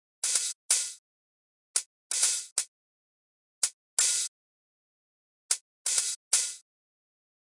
基本4/4节拍120bpm " 节拍120bpm02
描述：基本4/4击败120bpm
Tag: 回路 节奏 drumloop 120BPM 节拍 量化 有节奏 常规